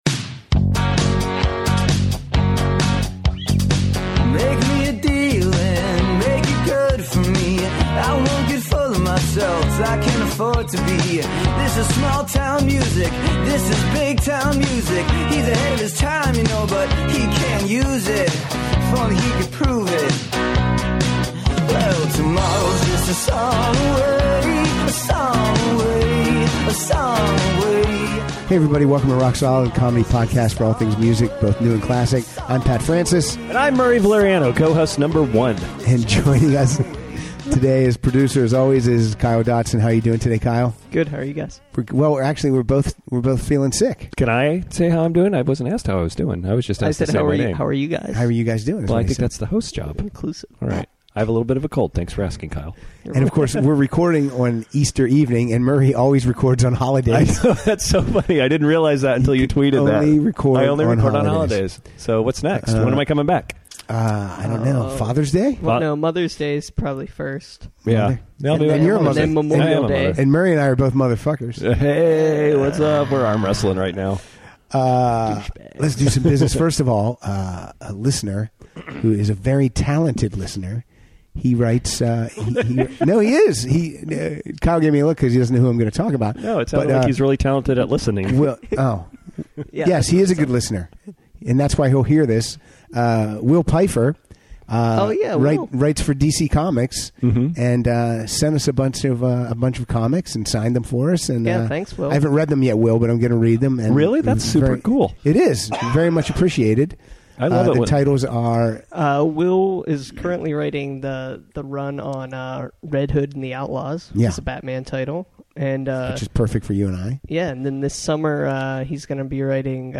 kick back open some brews and play their favorite songs about sunnin' and funnin'